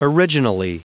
Prononciation du mot originally en anglais (fichier audio)
Prononciation du mot : originally